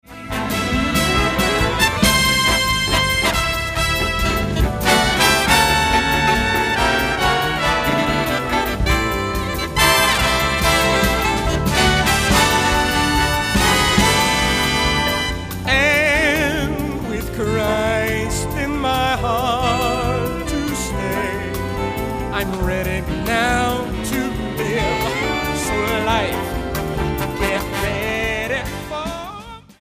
STYLE: Jazz